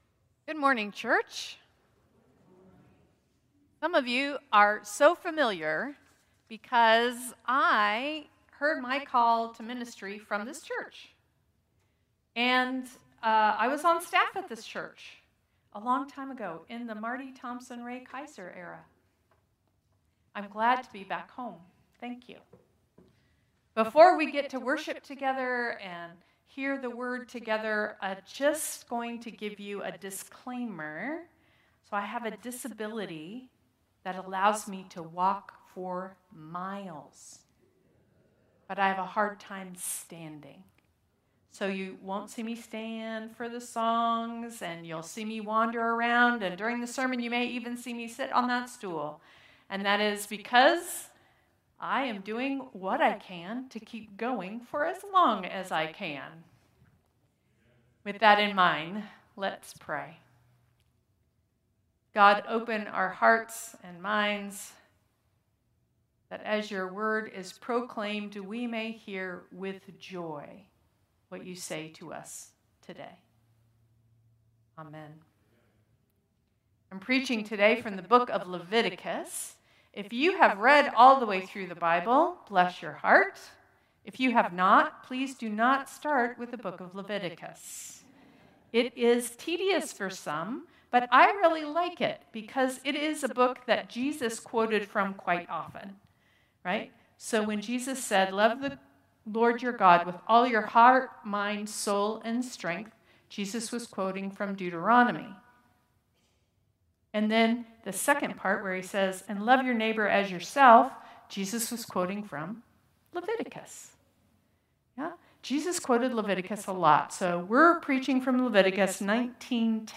Traditional Service 8/3/2025